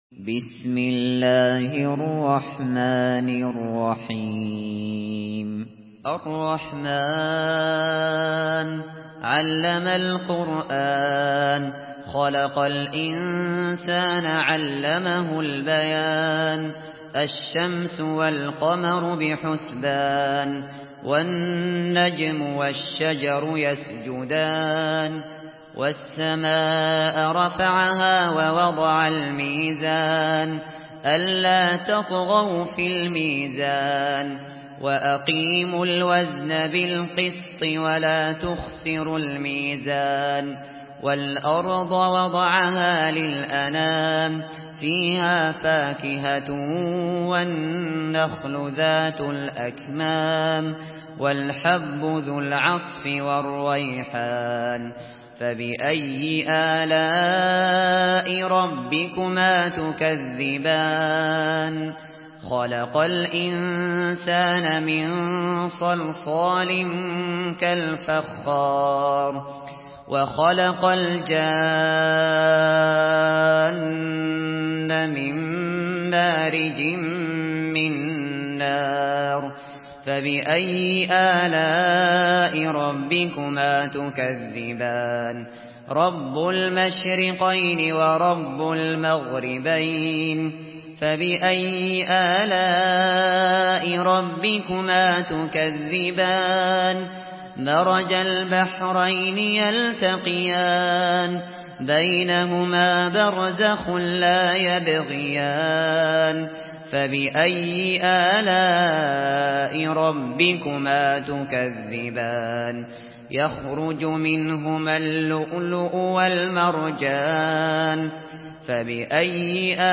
سورة الرحمن MP3 بصوت أبو بكر الشاطري برواية حفص عن عاصم، استمع وحمّل التلاوة كاملة بصيغة MP3 عبر روابط مباشرة وسريعة على الجوال، مع إمكانية التحميل بجودات متعددة.
مرتل